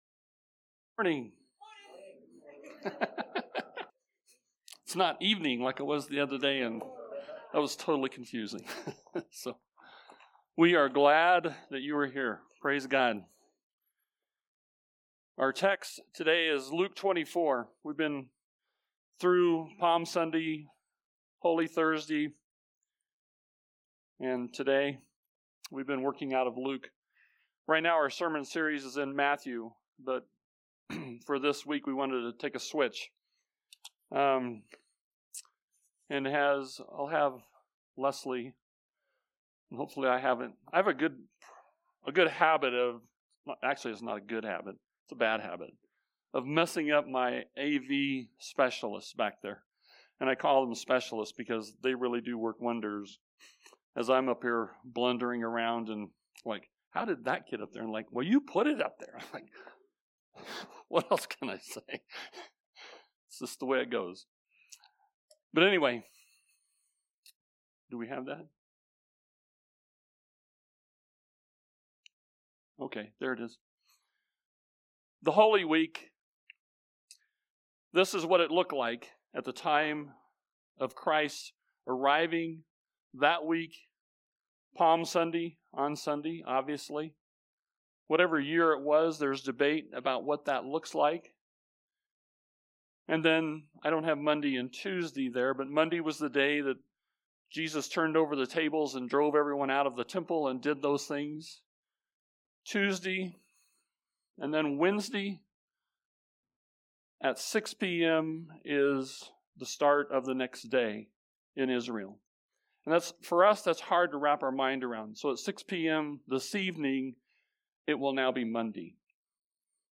Location: High Plains Harvest Church Passage: Romans 8:1-17